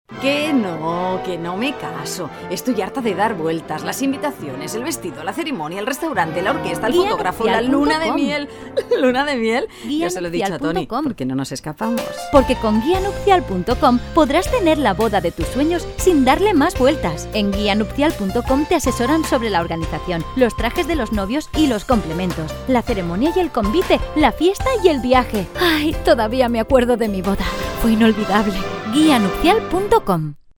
Campanya de Ràdio a Cadena 100